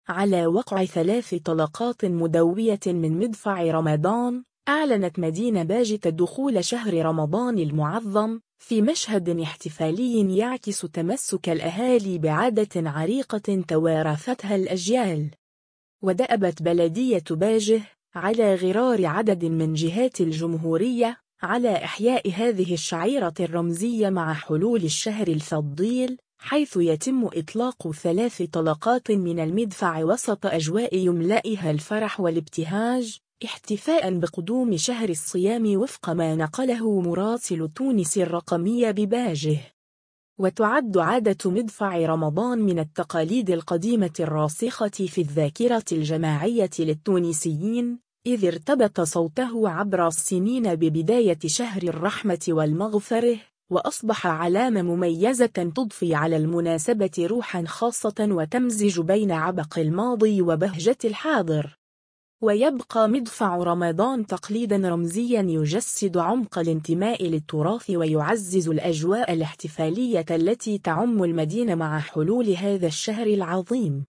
باجة : 3 طلقات مدفع تُعلن دخول شهر رمضان المعظم [فيديو]
على وقع ثلاث طلقات مدوية من مدفع رمضان، أعلنت مدينة باجة دخول شهر رمضان المعظم، في مشهد احتفالي يعكس تمسّك الأهالي بعادة عريقة توارثتها الأجيال.